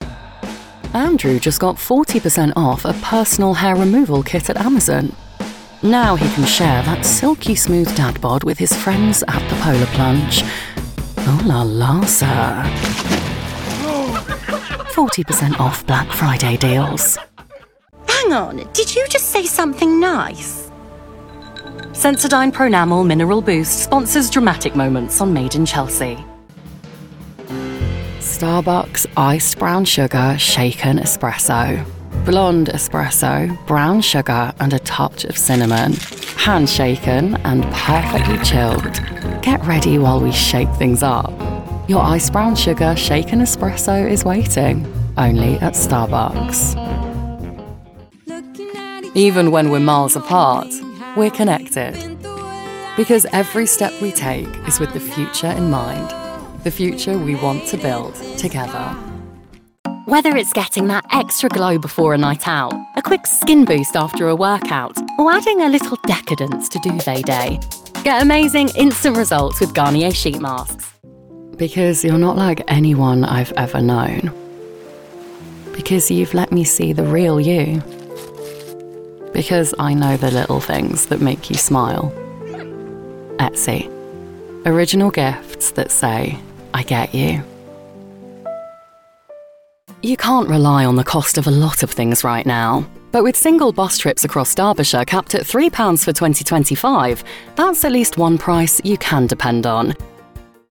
Male 30s , 40s , 50s British English (Native) Assured , Authoritative , Confident , Engaging , Natural , Warm , Versatile